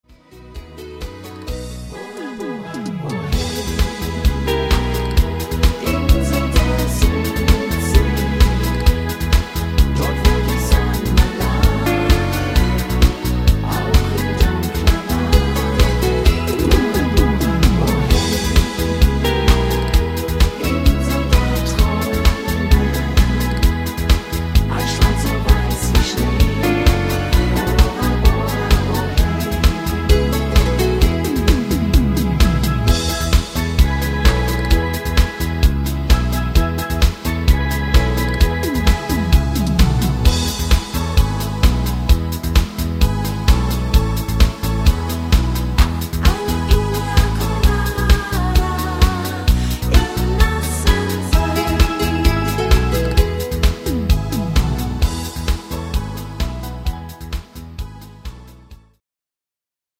Rhythmus  Discofox
Art  Deutsch, Duette